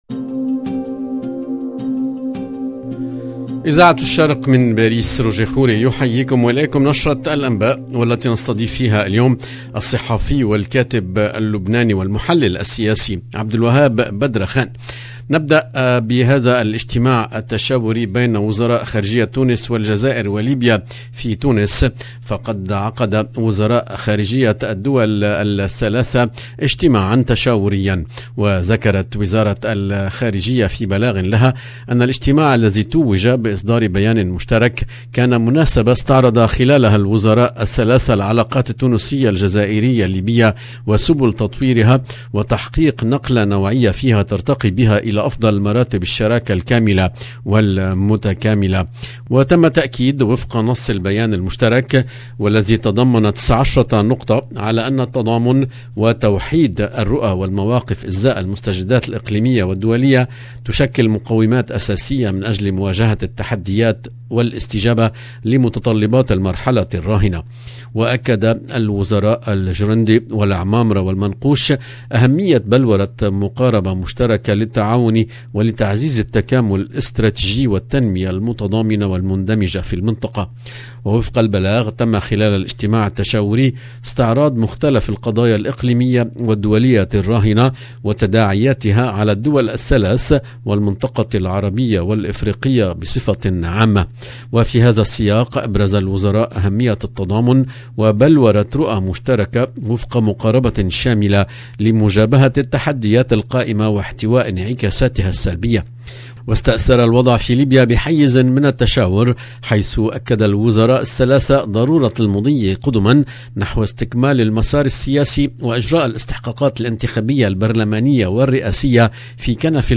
LE JOURNAL DU SOIR EN LANGUE ARABE DU 11/06/22